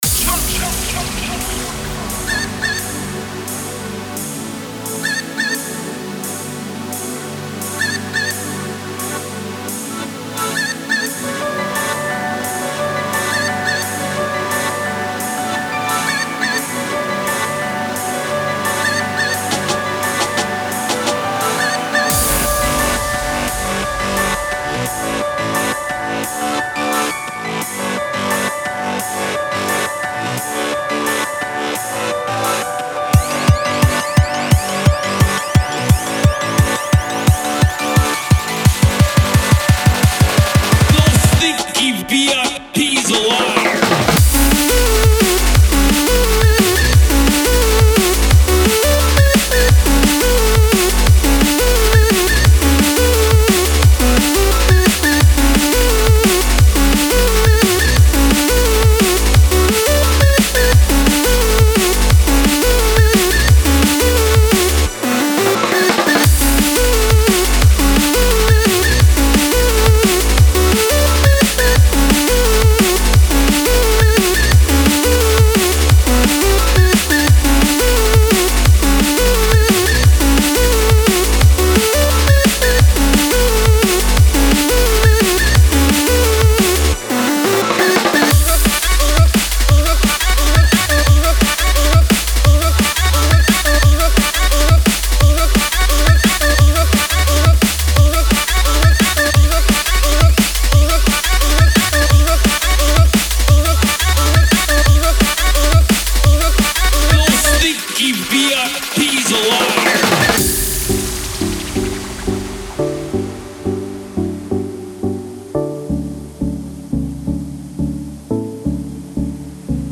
Drum & Bass, Energetic, Angry, Restless, Weird